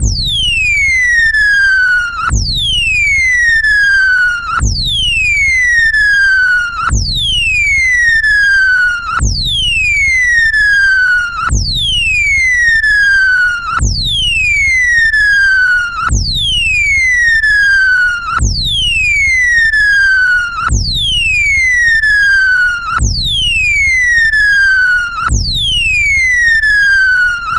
ReeltoReel Tape Manipulation/Noise Loops » TearThunk
描述：whistling stomping ripping squeakpunctuated recorded on 1/4" tape and physically looped
标签： tapemanipulation ripping squeak stomp tapeloop whistle
声道立体声